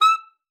Index of /90_sSampleCDs/Best Service ProSamples vol.42 - Session Instruments [AIFF, EXS24, HALion, WAV] 1CD/PS-42 WAV Session Instruments/Saxophone stacc piano
42h-sax14-e6.wav